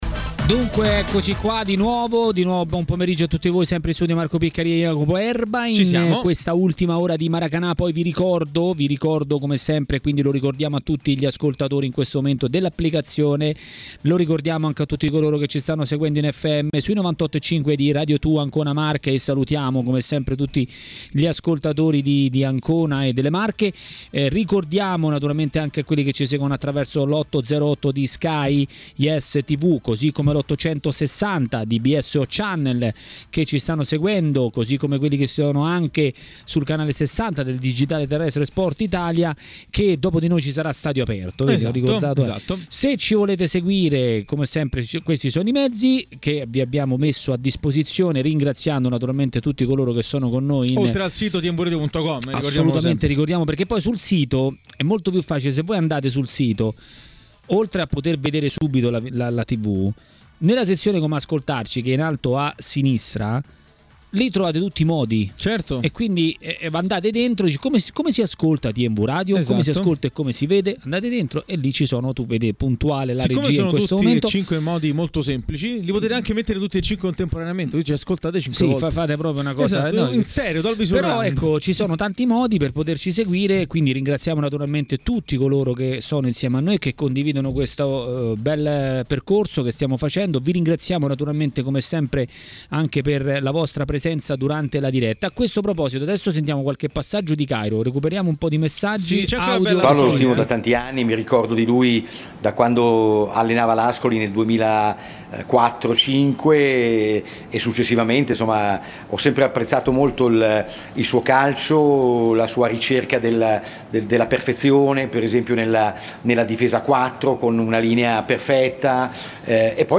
Ai microfoni di Tmw Radio nel corso di 'Maracanà' è intervenuto Ciccio Graziani, ex calciatore di Torino e Roma.